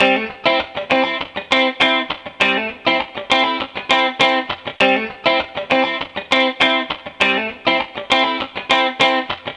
Sons et loops gratuits de guitares rythmiques 100bpm
Guitare rythmique 45